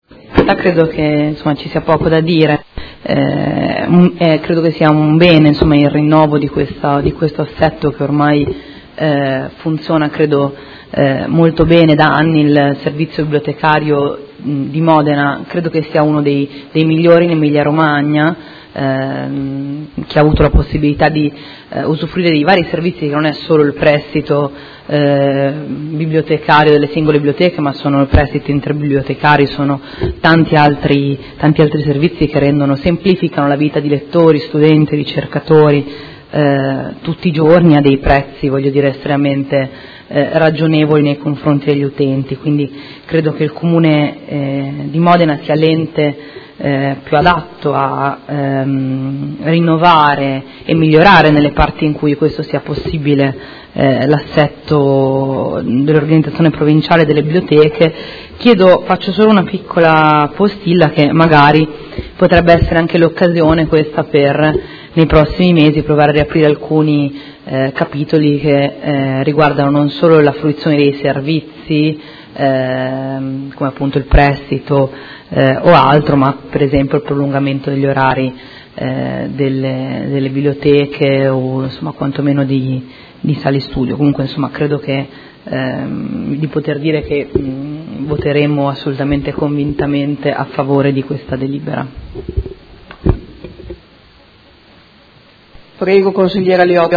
Seduta del 19/05/2016. Proposta di deliberazione avente come oggetto: Convenzione Polo bibliotecario provinciale - Approvazione. Dibattito